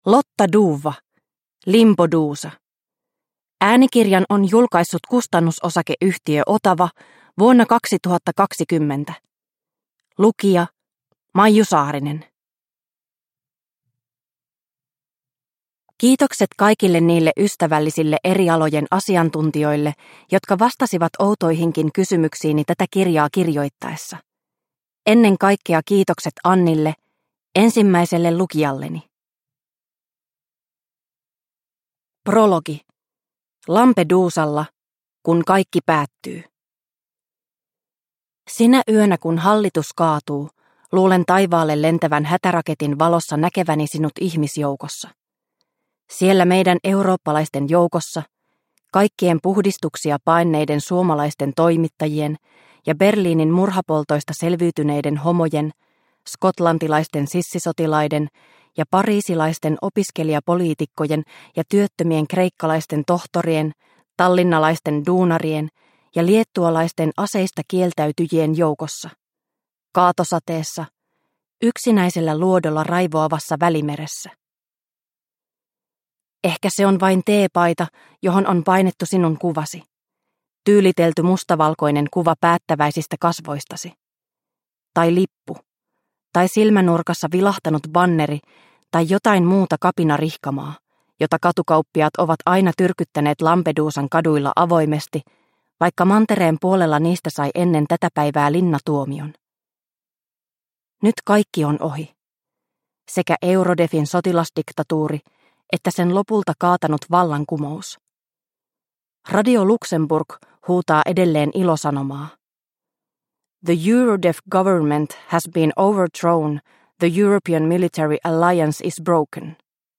Limbodusa – Ljudbok – Laddas ner